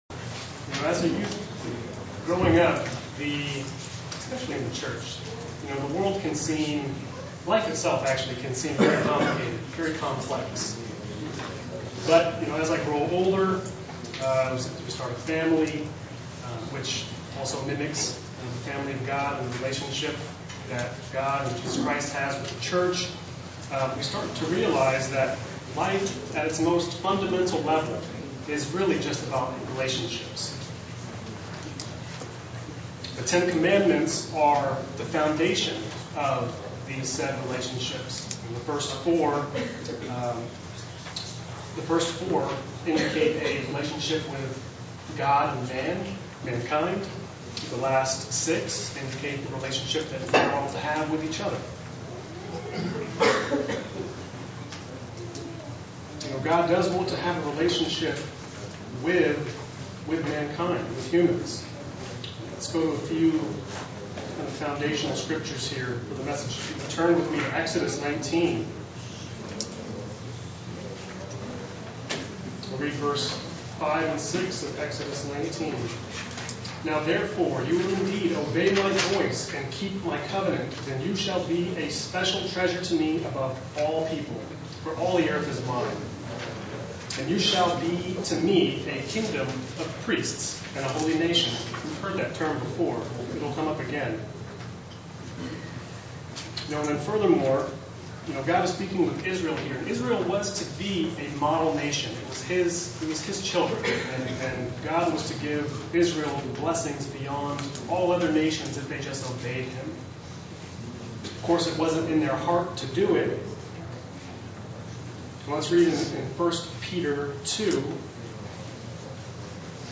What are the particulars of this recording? Day three of the Feast of Tabernacle in Estonia SEE VIDEO BELOW